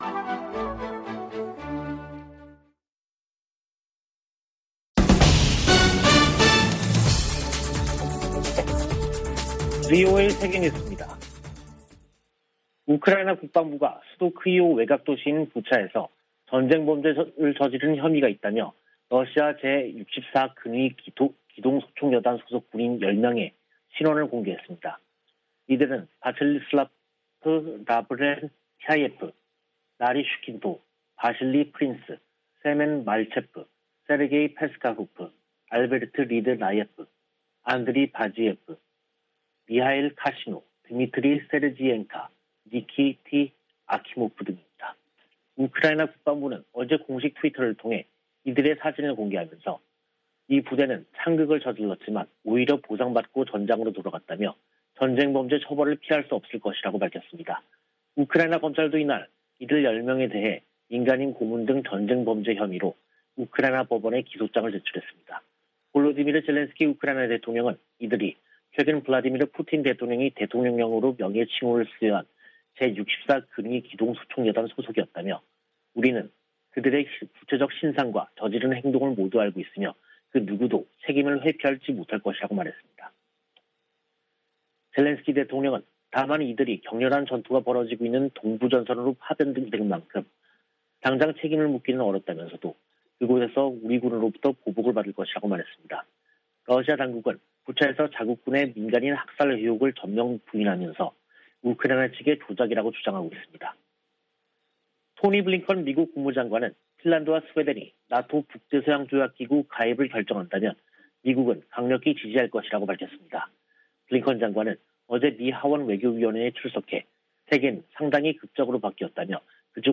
VOA 한국어 간판 뉴스 프로그램 '뉴스 투데이', 2022년 4월 29일 2부 방송입니다. 다음 달 미한 정상회담에서 동맹강화와 대북공조 등이 중점 논의될 것으로 보입니다. 김정은 북한 국무위원장의 ‘핵무력 강화’ 발언에 대해 미국은 핵 억지력과 미사일 방어망을 강화해야 한다고 상원 외교위원회 공화당 간사가 강조했습니다.